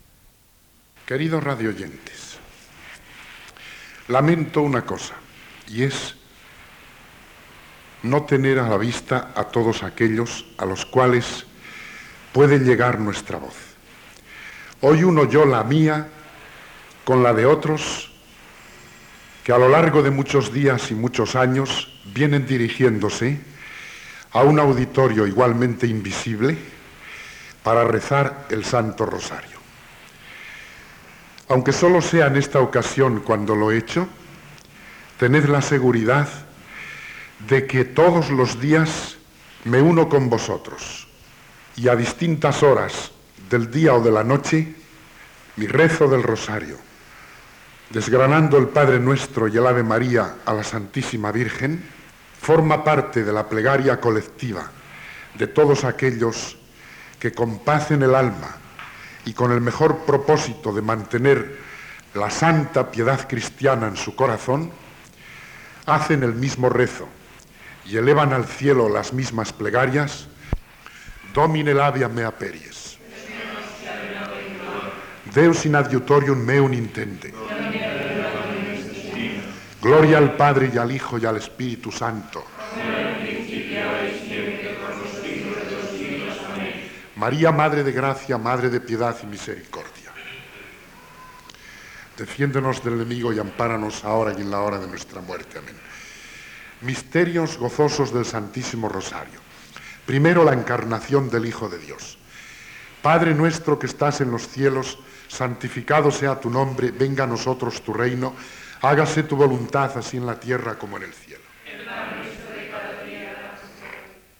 Introducció i res del Rosari
Religió